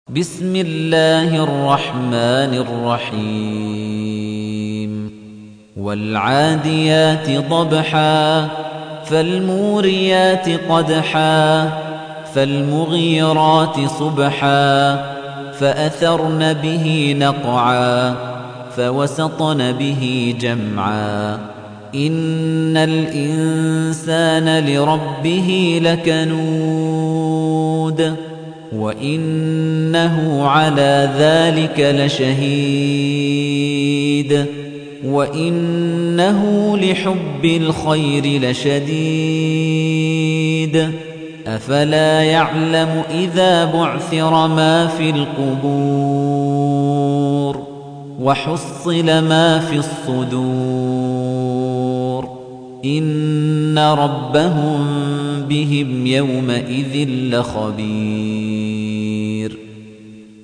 تحميل : 100. سورة العاديات / القارئ خليفة الطنيجي / القرآن الكريم / موقع يا حسين